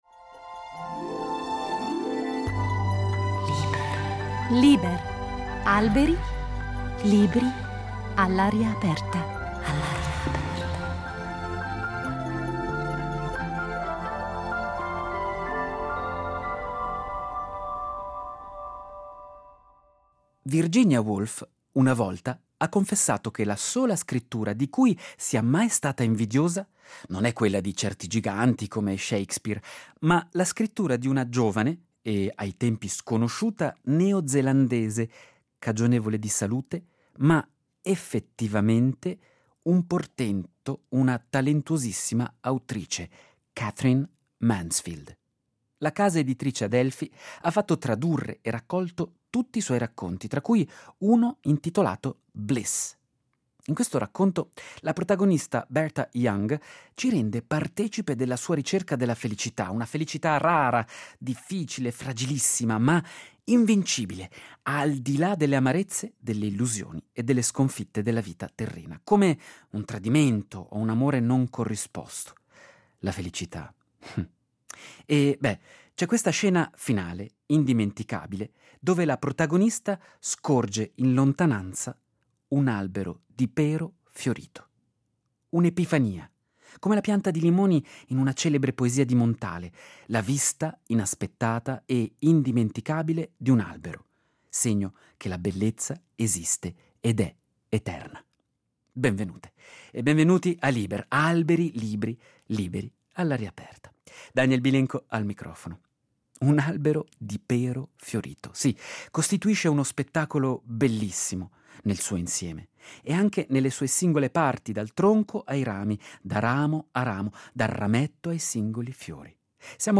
Con gli innesti botanici all’aria aperta dell’esperta di piante tossiche e curative